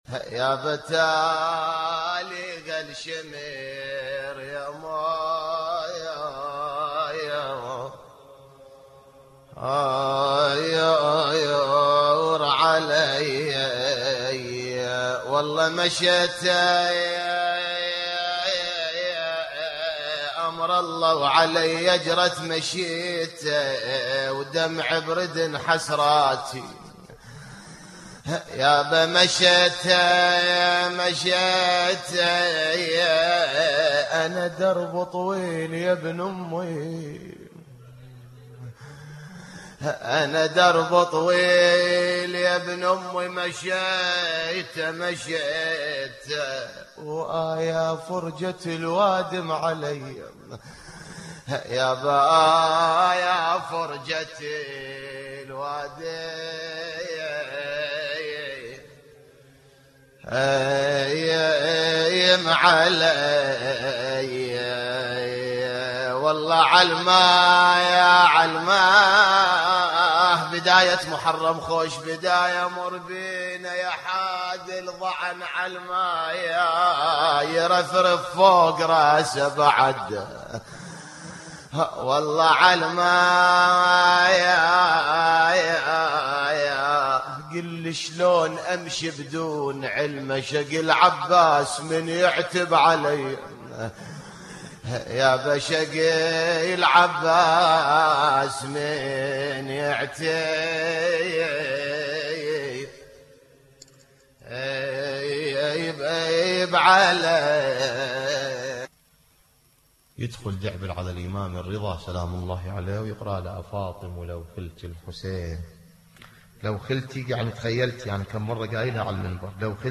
للتحميل This entry was posted in نواعي